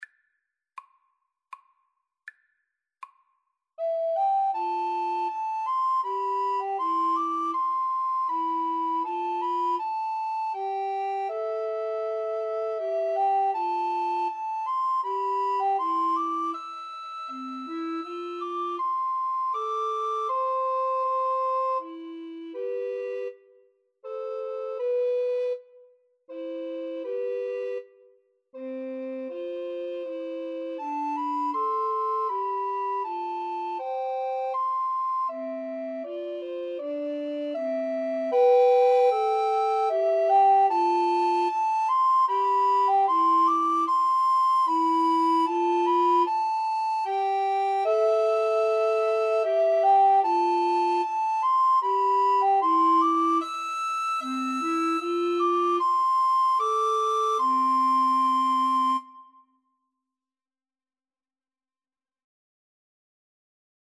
Free Sheet music for Recorder Trio
Alto RecorderTenor Recorder 1Tenor Recorder 2
3/4 (View more 3/4 Music)
Andante
Classical (View more Classical Recorder Trio Music)